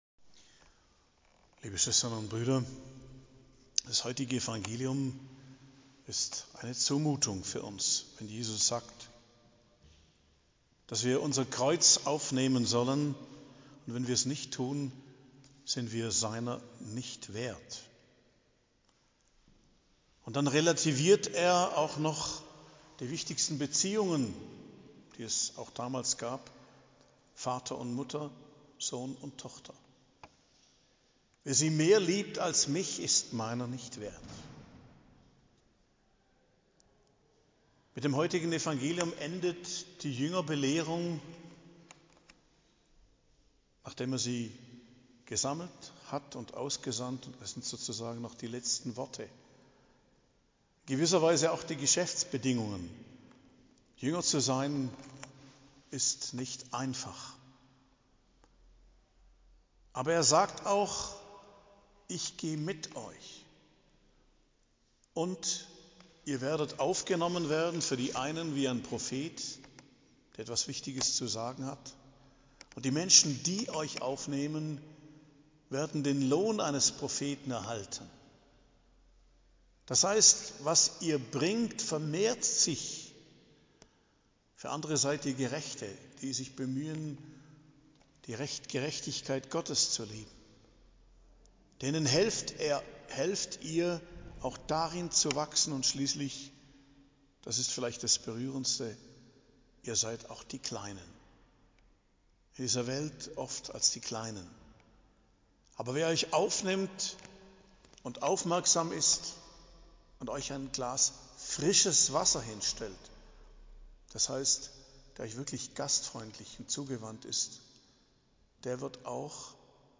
Predigt zum 13. Sonntag i.J., 2.07.2023